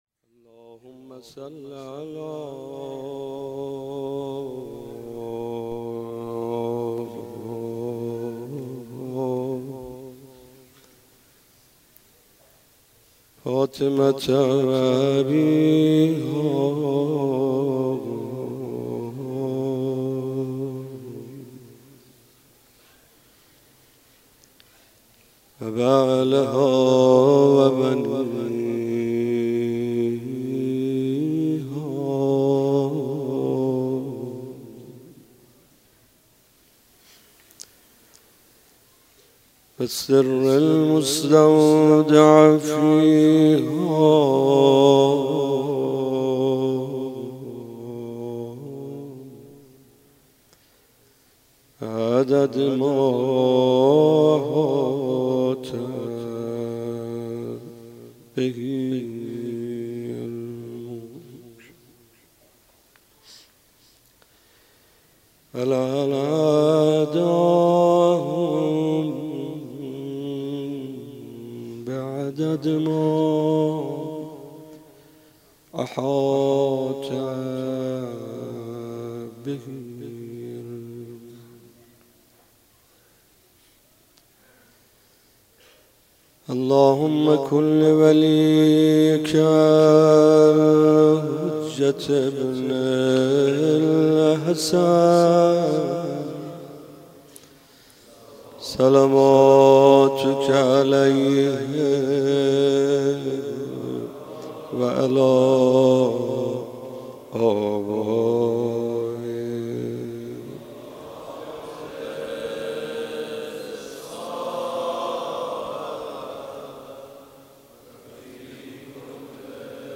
مداح : محمدرضا طاهری قالب : روضه